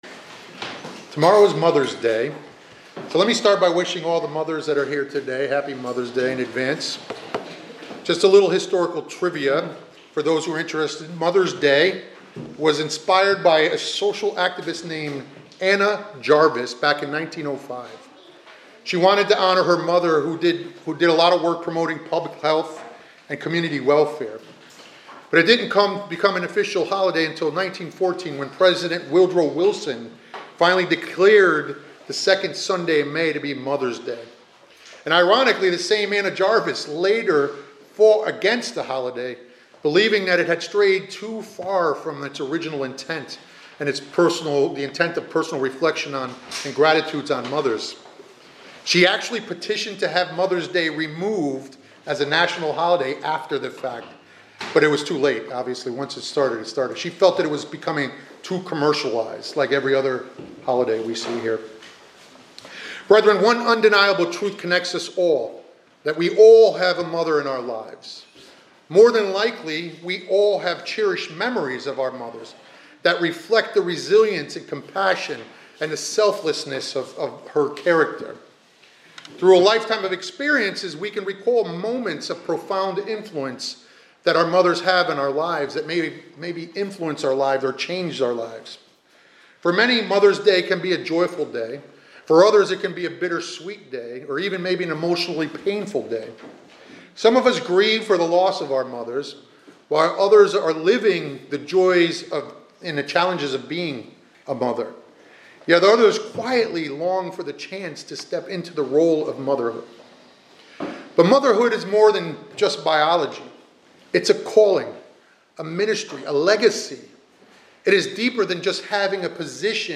This sermon is a tribute to the mothers of the Bible—women whose lives and choices have left a lasting impact both spiritually and personally.